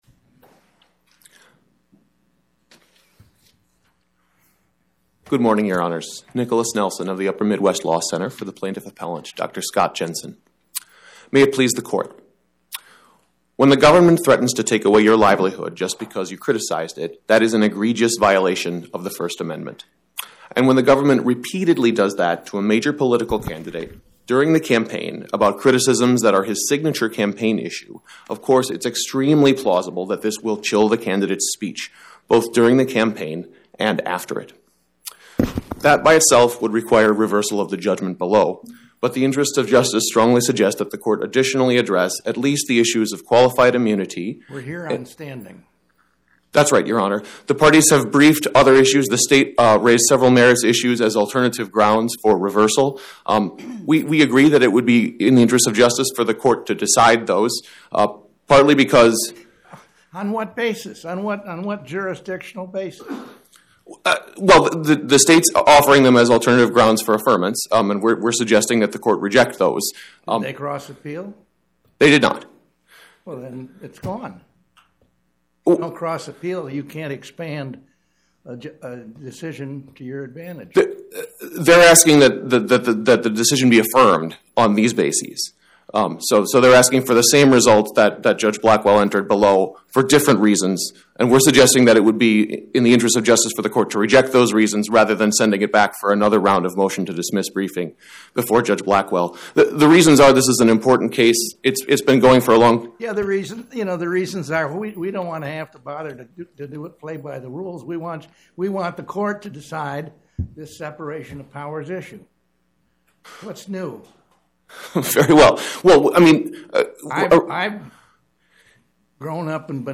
25-1812: Dr. Scott Jensen vs Minn. Bd. of Medical Practice Podcast: Oral Arguments from the Eighth Circuit U.S. Court of Appeals Published On: Wed Feb 11 2026 Description: Oral argument argued before the Eighth Circuit U.S. Court of Appeals on or about 02/11/2026